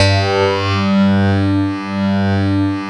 66 CLAV   -R.wav